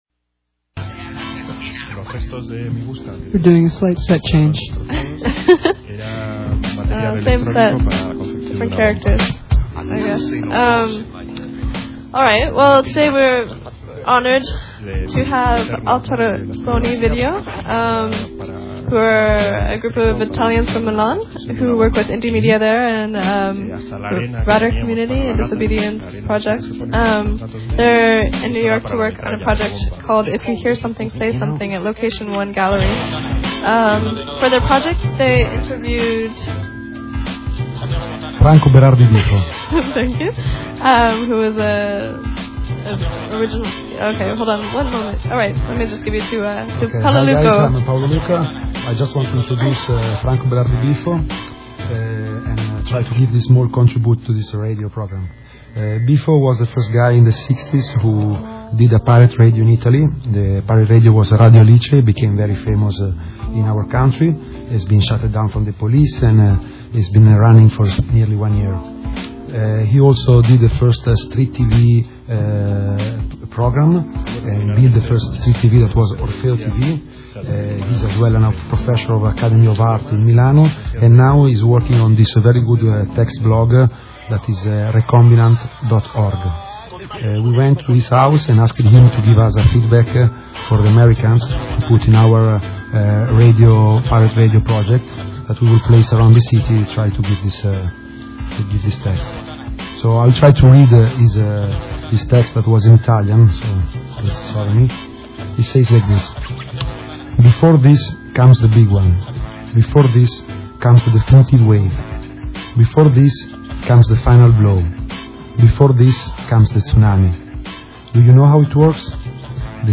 Alterazioni Video collective interviewed by August Sound Coalition (Audio)